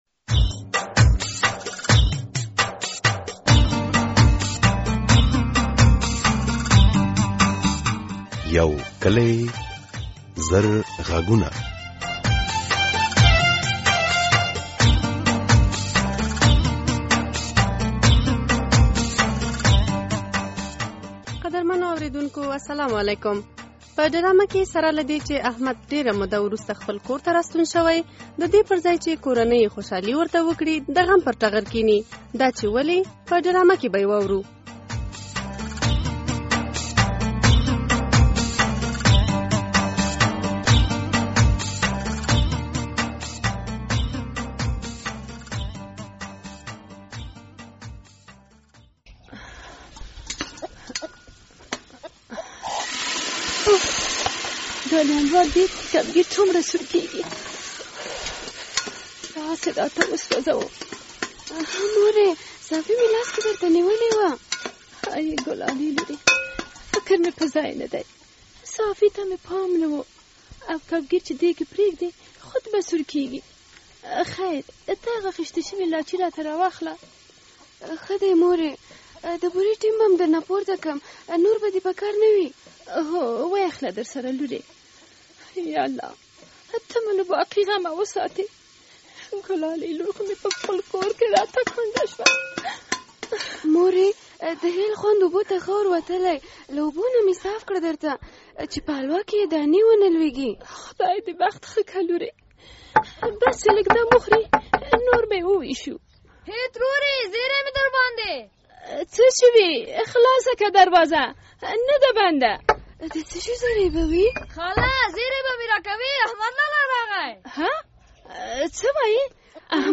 د ازادي راډيو د یو کلي زرغږونو ډرامې په ۱۰۹ برخه کې همدې موضوع ته اشاره شوي.